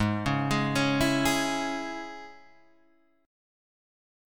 G#sus4#5 Chord